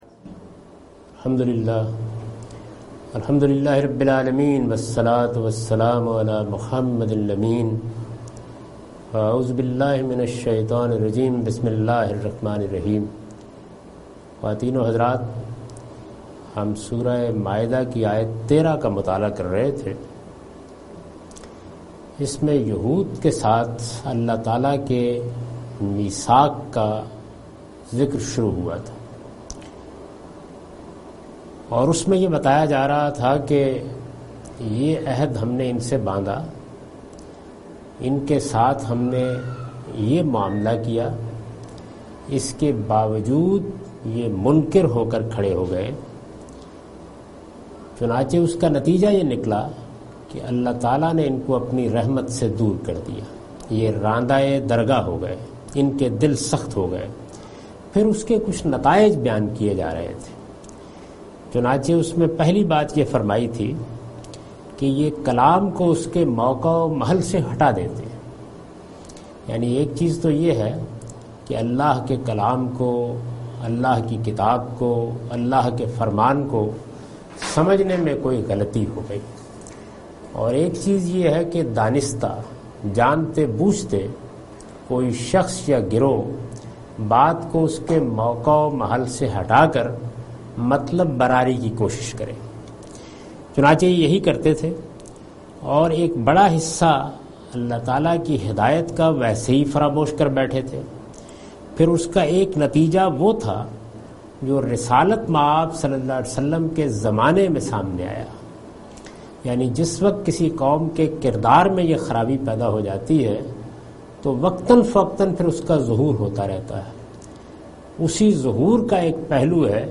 Surah Al-Maidah - A lecture of Tafseer-ul-Quran, Al-Bayan by Javed Ahmad Ghamidi.